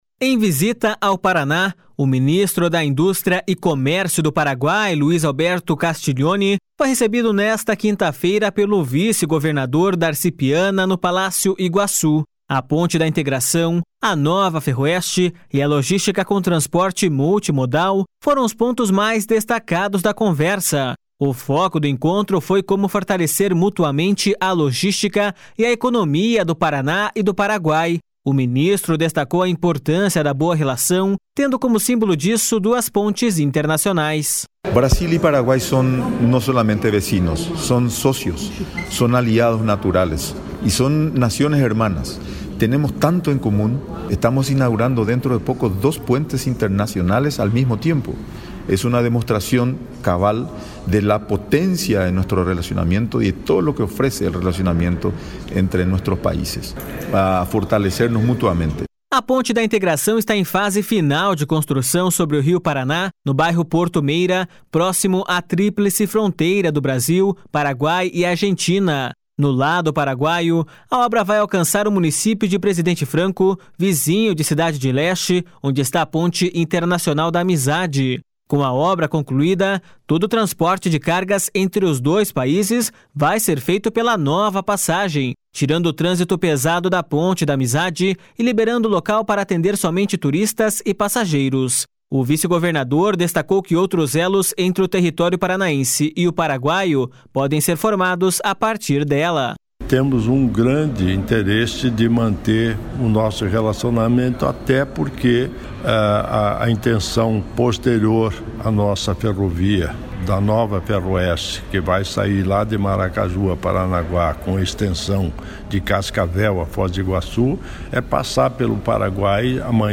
//SONORA LUIS ALBERTO CASTIGLIONE//
//SONORA DARCI PIANA//